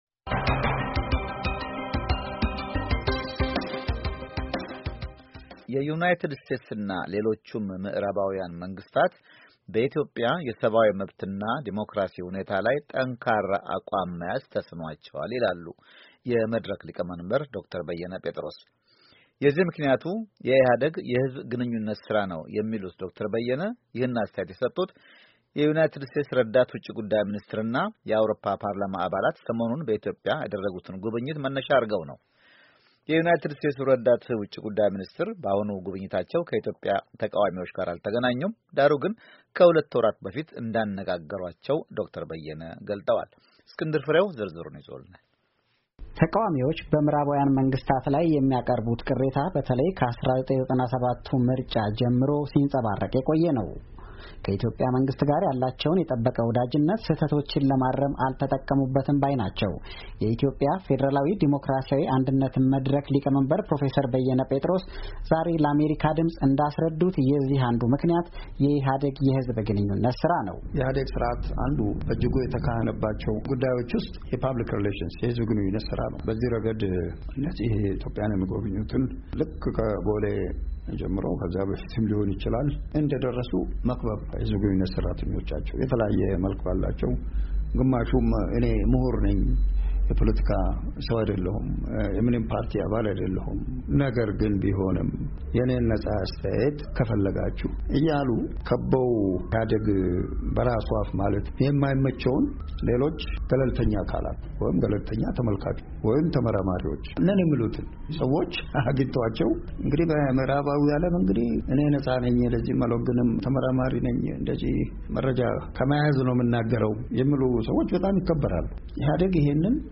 ዘገባ